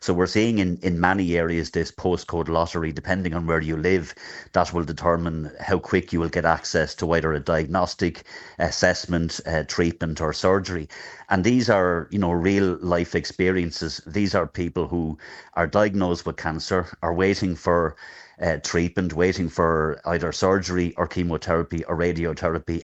Sinn Féin spokesperson for Health, David Cullinane, says things need to change: